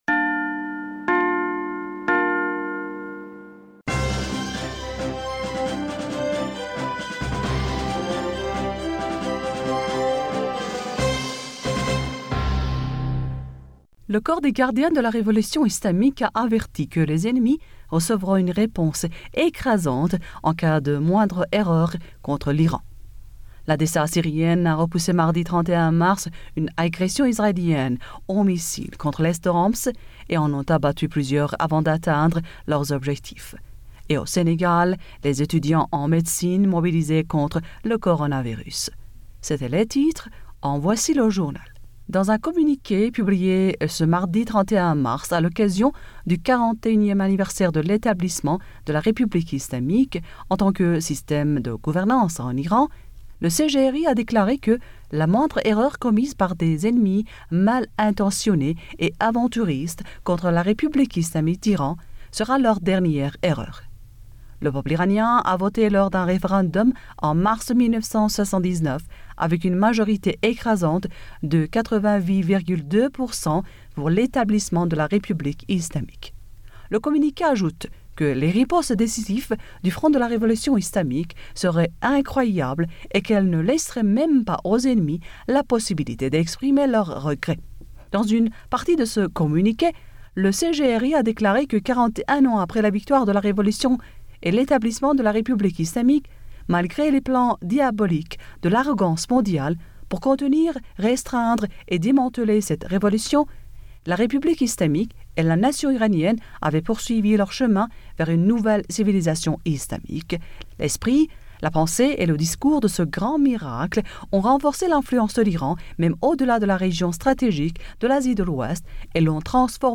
Bulletin d'information du 01 avril 2020